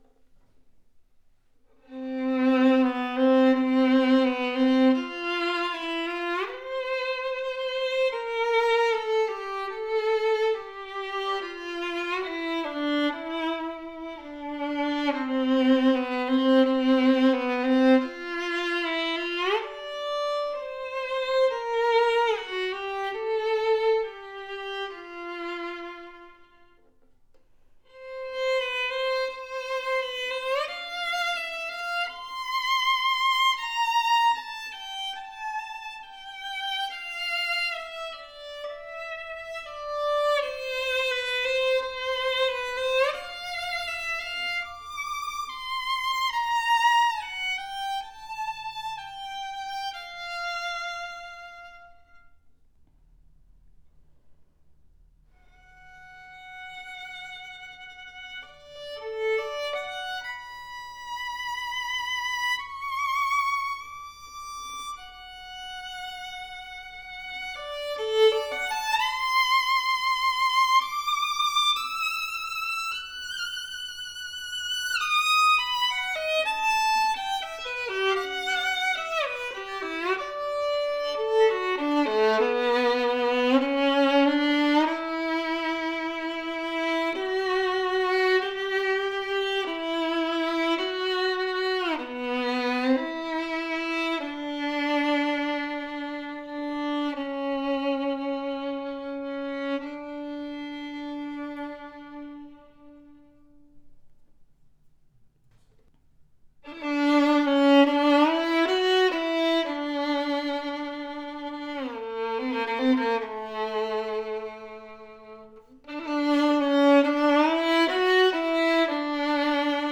• Classic Italian antique reddish varnish, tone oriented.
A RARE fine sounding violin at this price range, special edition made after the Gagliano pattern, that delivers a surprising RESONANT,  WARM and PROJECTIVE tone. Exquisite antique REDDISH varnish, full and extra higher arching creates a powerful tone with deep and bold projection.
*A special edition with reddish varnish, viola alike lower register with dark projective tone.